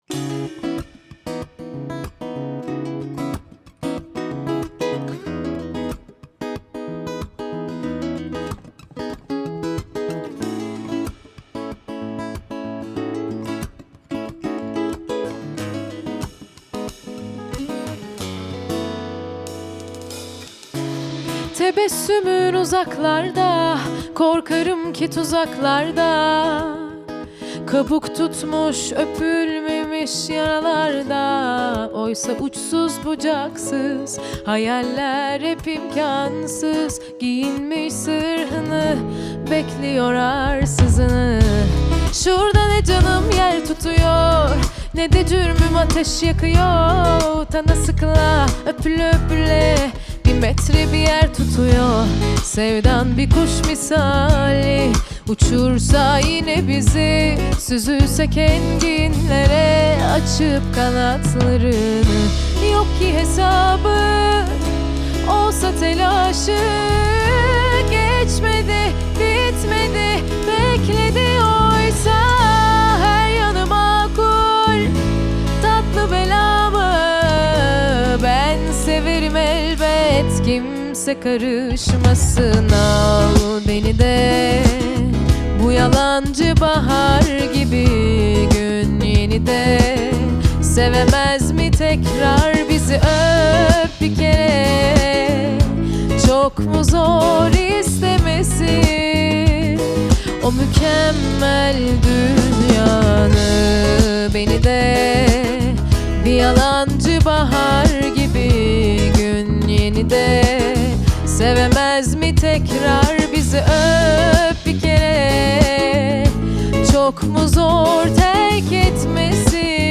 Guitar
Drums